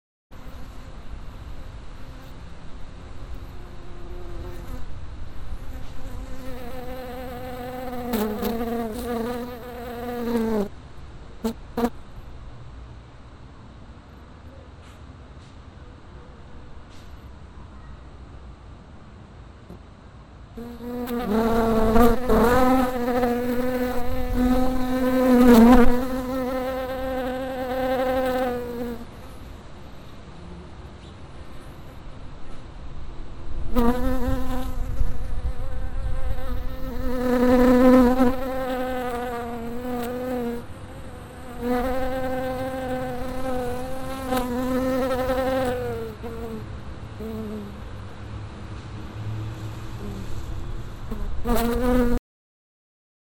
3D spatial surround sound "Buzzing fly"
3D Spatial Sounds